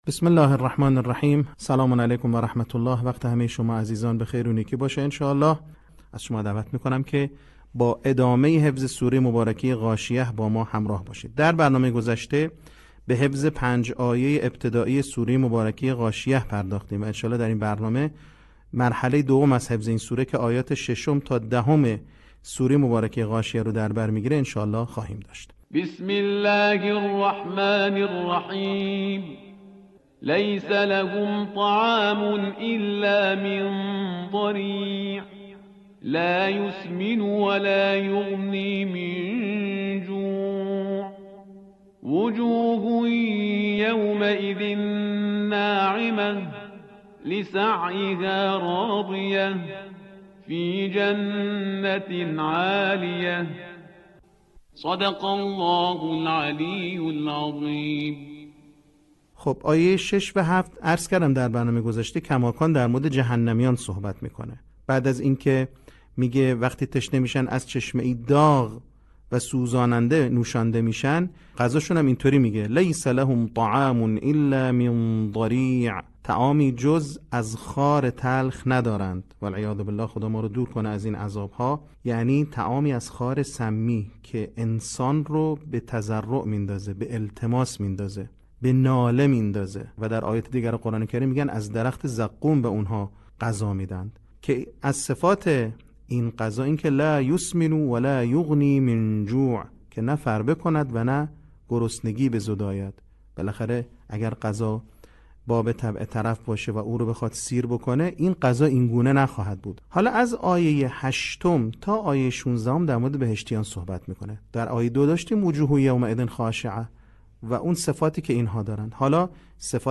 صوت | بخش دوم آموزش حفظ سوره غاشیه